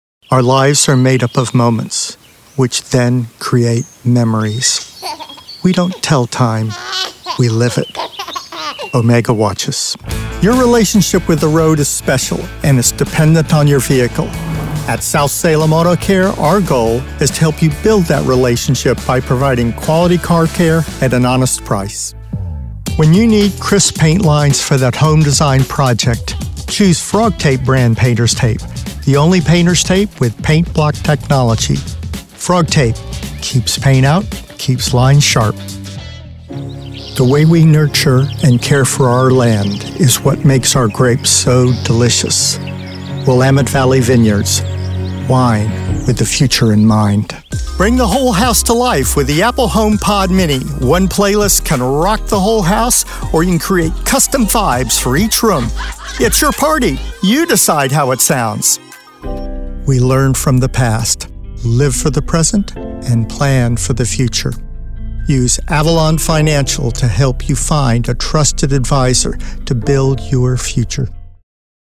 American Male Tenor Voice Artist
englisch (us)
Sprechprobe: Werbung (Muttersprache):